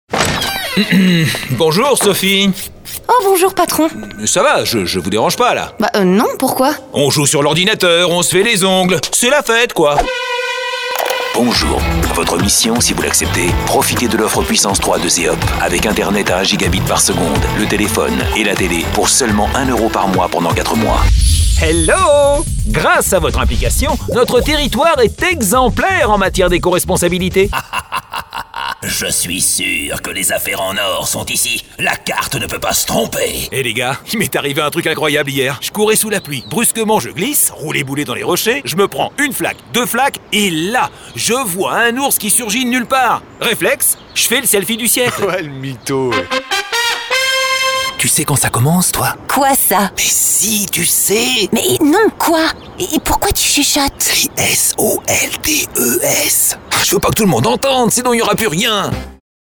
Hello, I'm a french voice over with warm and deep voice for the narration.
Multiple characters for cartoon and video games, Sexy and fun for commercial.
Sprechprobe: Sonstiges (Muttersprache):